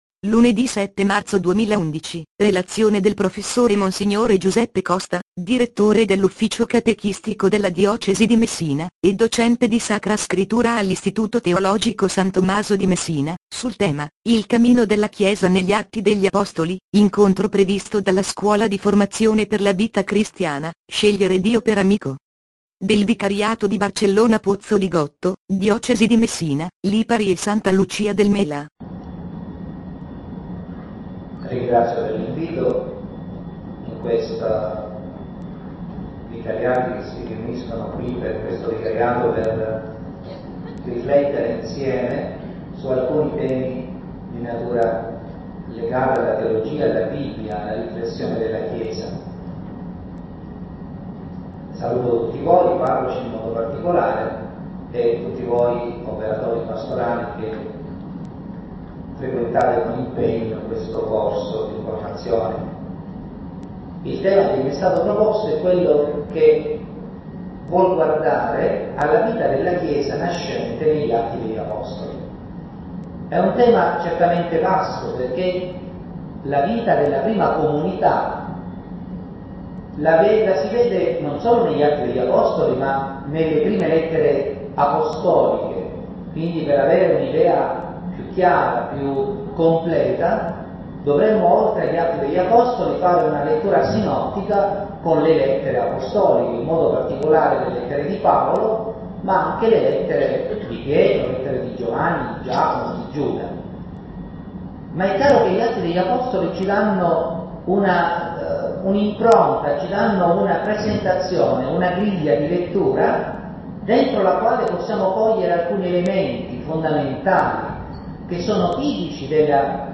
Scuola di Formazione per la Vita Cristiana